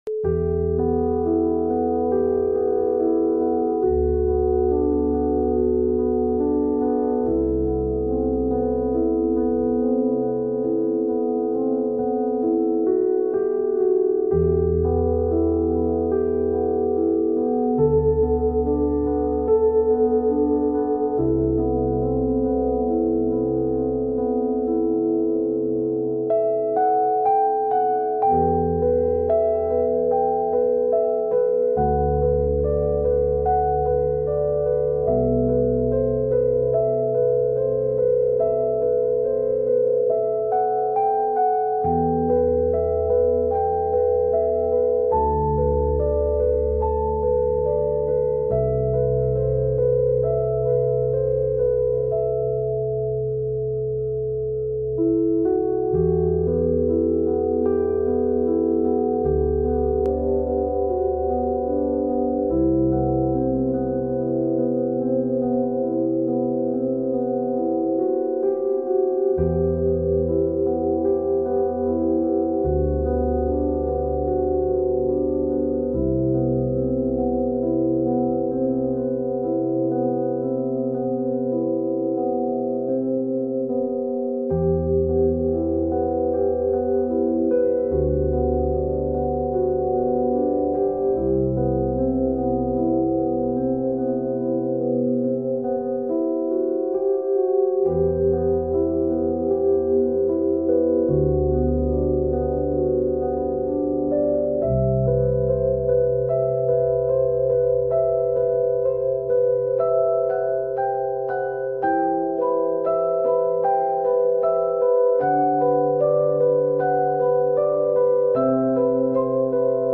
an acoustic frequency composition designed to support relaxation, inner balance, and meditative states. Piano Composition: FI6 – Where You Were This audio sequence is crafted based on psychoacoustic principles. It combines harmonic sine tones with binaural beat frequencies to induce a Theta state.
Clarity, “light,” spiritual openness – high-frequency sine tones